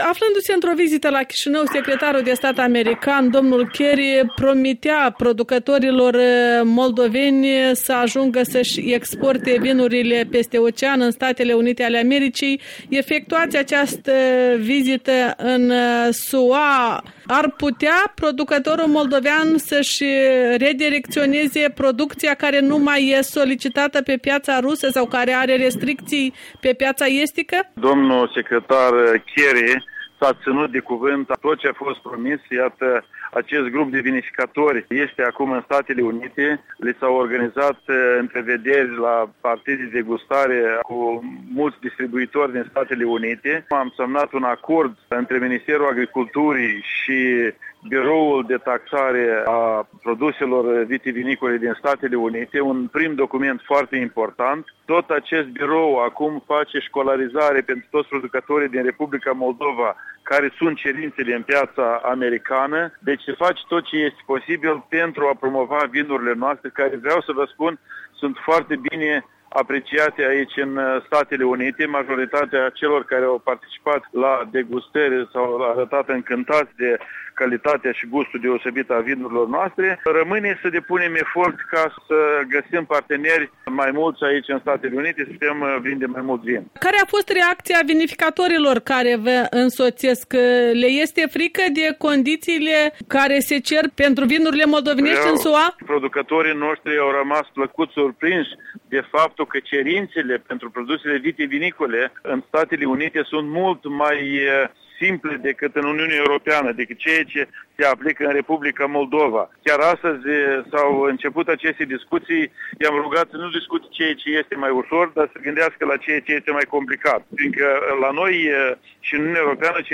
Interviu cu ministrul agriculturii Vasile Bumacov